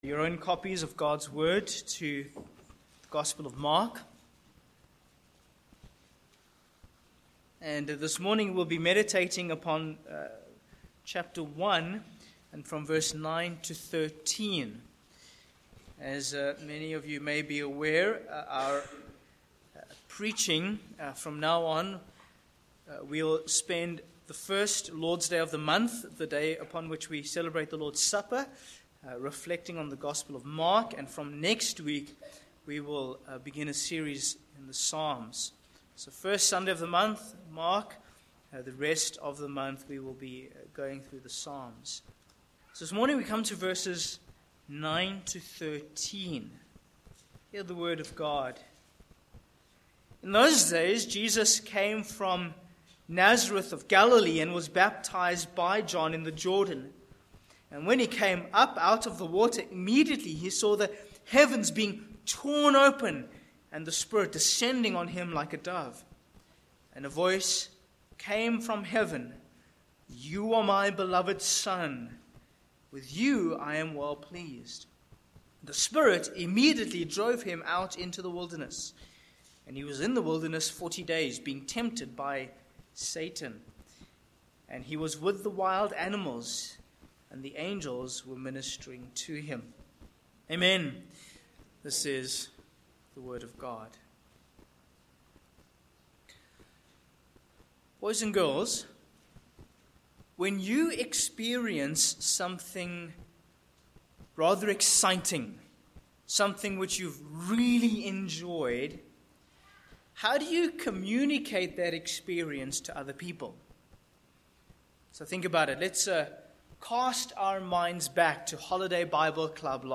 Sermon points: 1. Heaven’s Witness v9-11 2. A Proven King v12-13